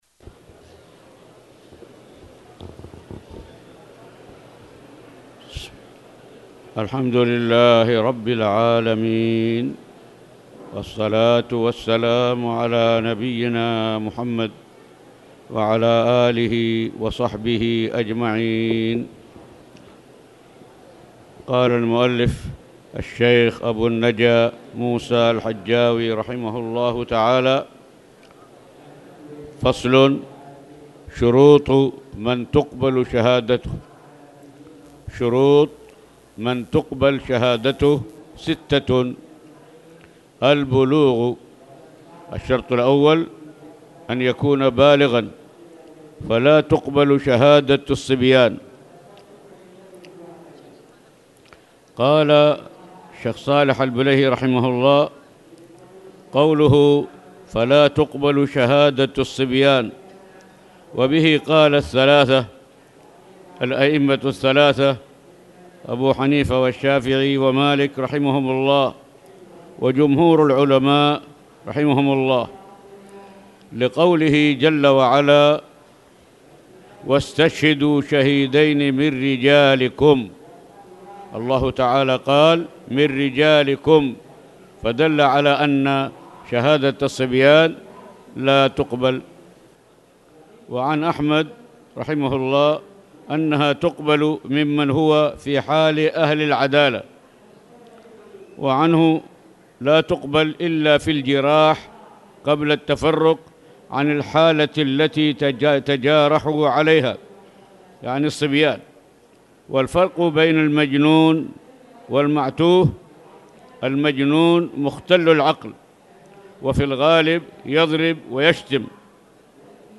تاريخ النشر ٢٨ ربيع الأول ١٤٣٨ هـ المكان: المسجد الحرام الشيخ